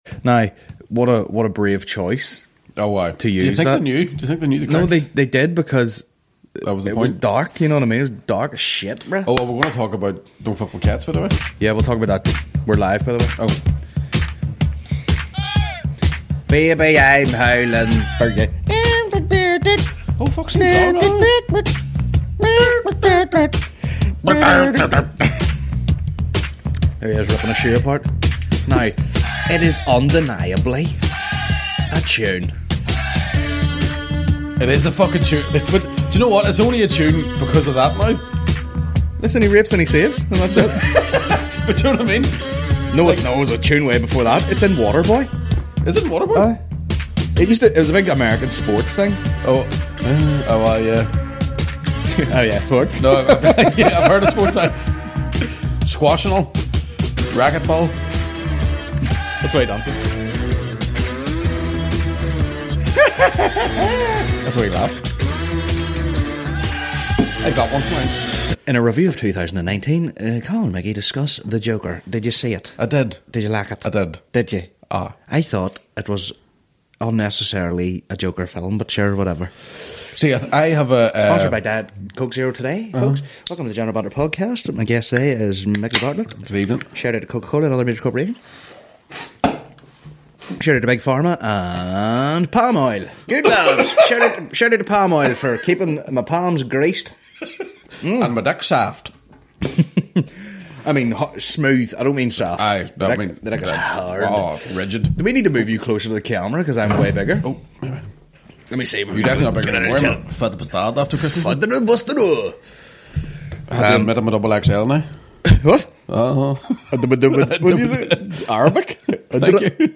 a Comedy podcast